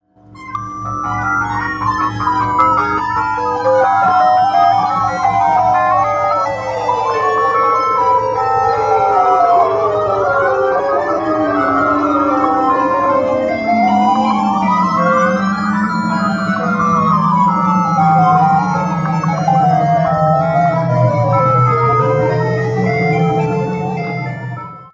Data sonification for population of Australia,US, Japan and Finland from the 1980's and 2000
all mixed down.wav